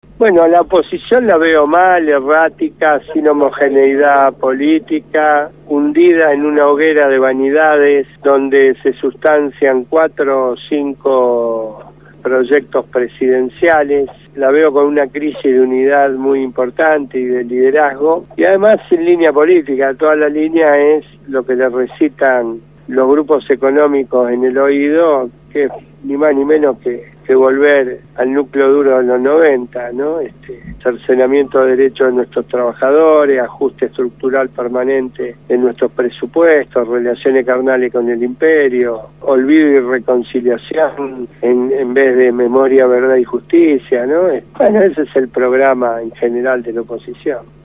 El dirigente social Luis D´Elía fue entrevistado en «Voces Portuarias» (Martes, de 18:00 a 19:00hs, programa del Sindicato Único de Portuarios de Argentina).